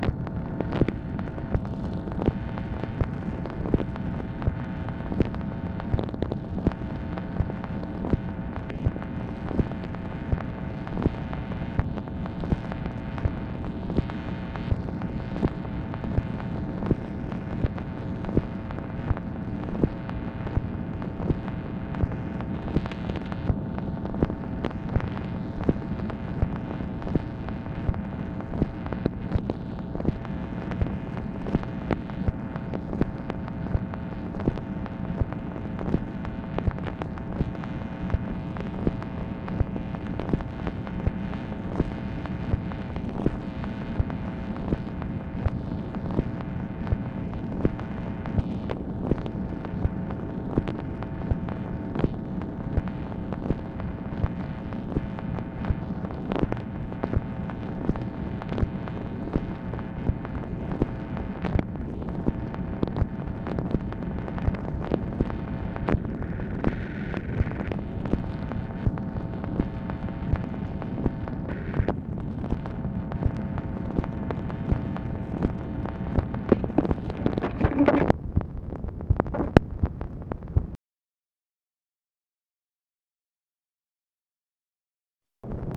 MACHINE NOISE, November 11, 1966
Secret White House Tapes | Lyndon B. Johnson Presidency